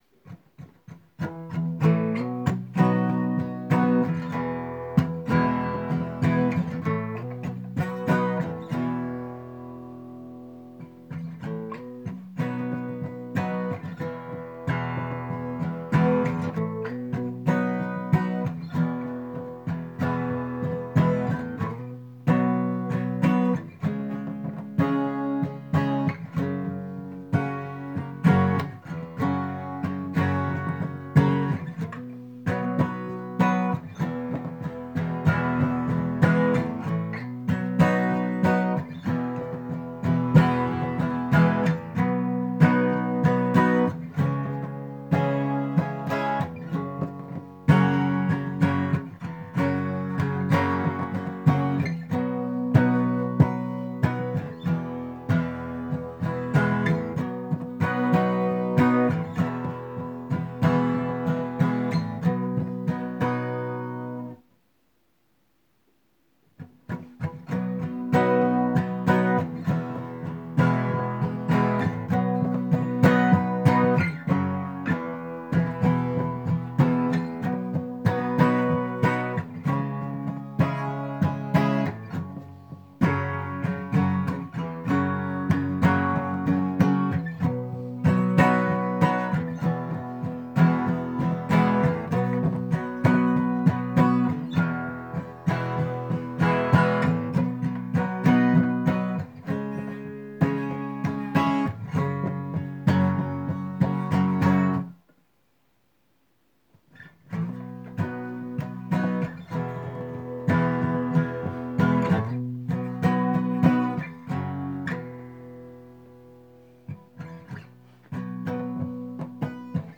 music_smorgasbord_opengate_acoustic.m4a